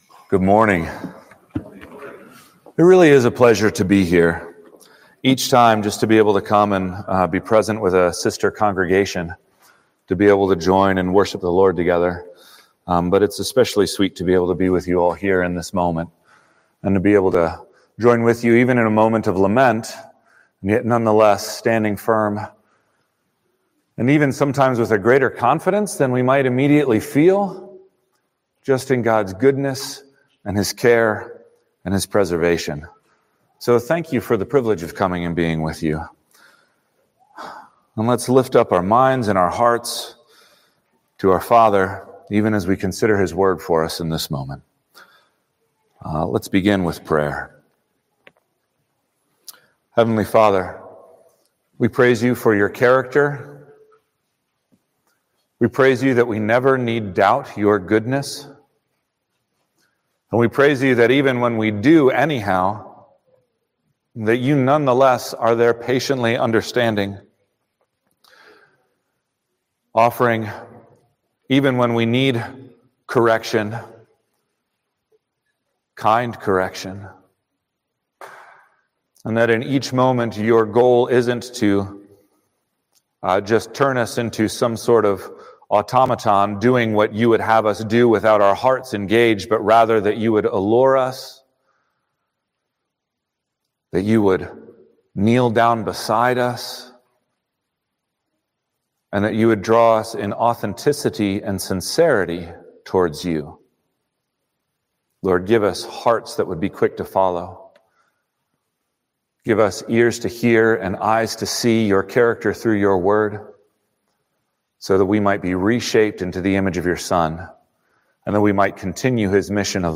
From Series: “Guest Sermons“